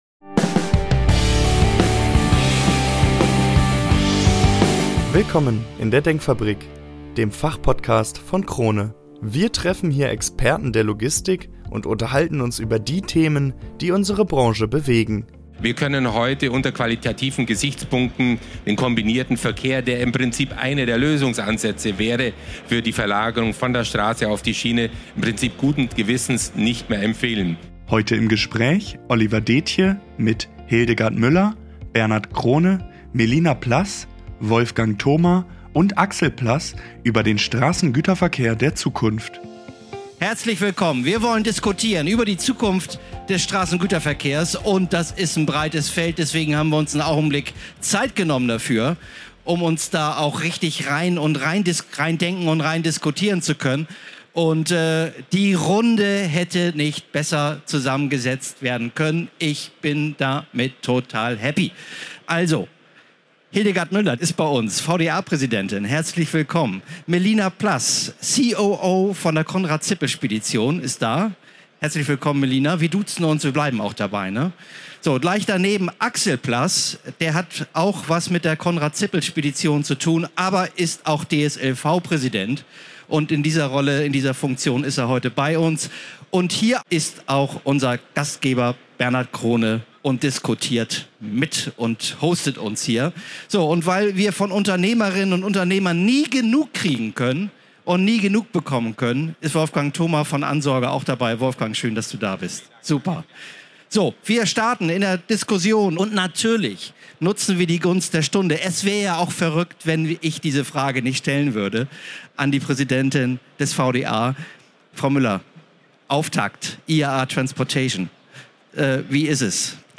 Der Straßengüterverkehr der Zukunft - Expertendiskussion ~ DENKFABRIK Podcast